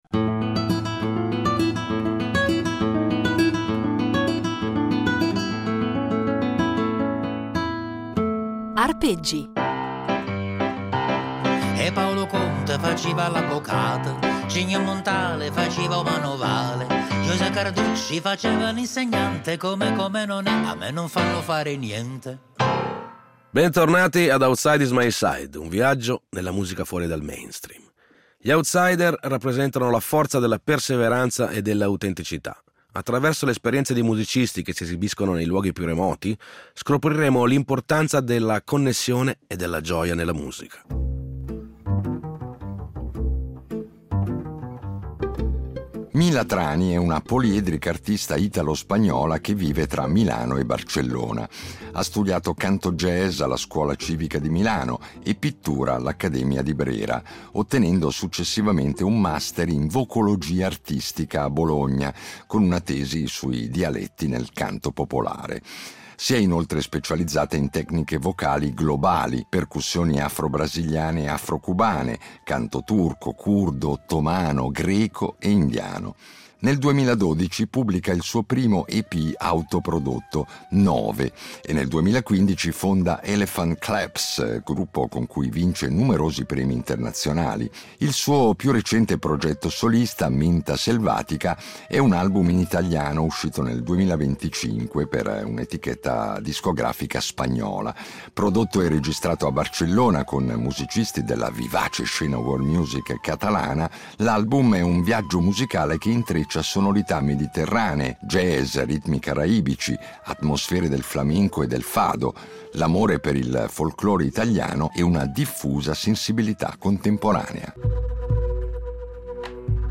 Sì, divertire, perché la musica d’autore è anche molto divertente e in queste 10 puntate ce ne accorgeremo, grazie alla disponibilità di dieci outsider o presunti tali, che ci offriranno dal vivo anche un assaggio della loro arte.